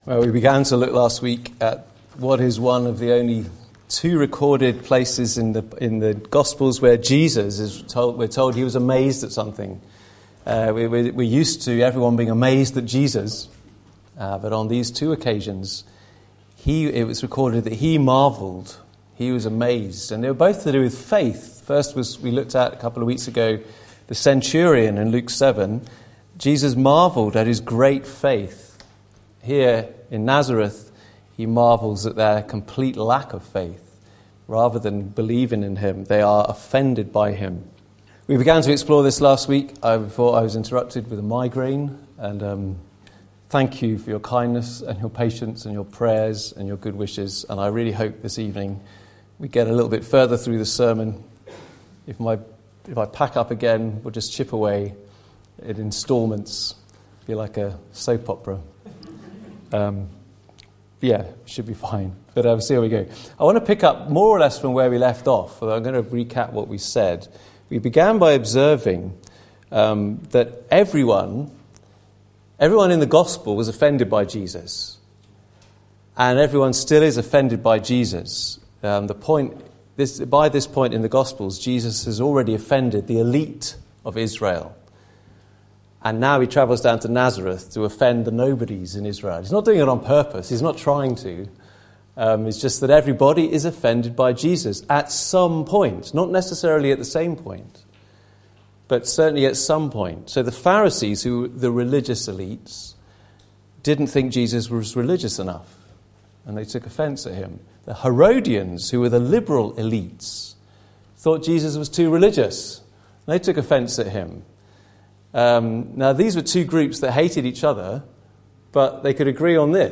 Encounters with Jesus Passage: Mark 6:1-6 Service Type: Sunday Evening « Beware.